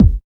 71 KICK.wav